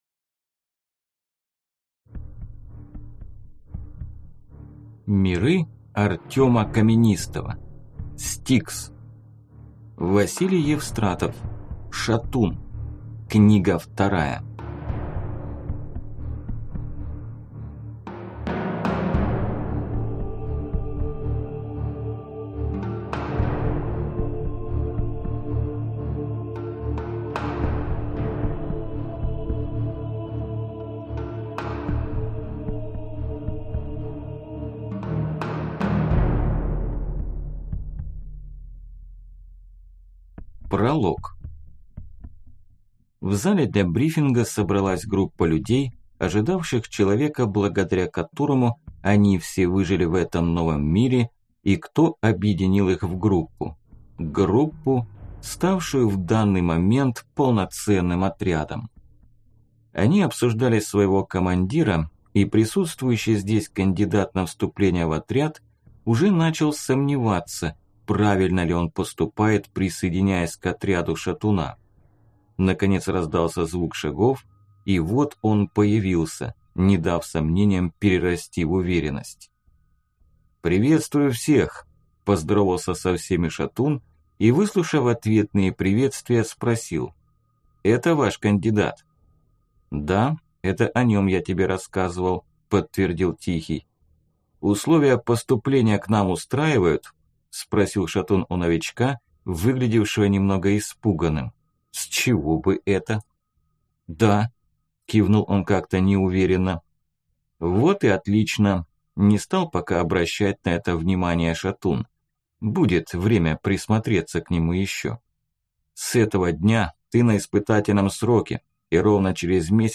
Аудиокнига S-T-I-K-S. Шатун. Книга 2 | Библиотека аудиокниг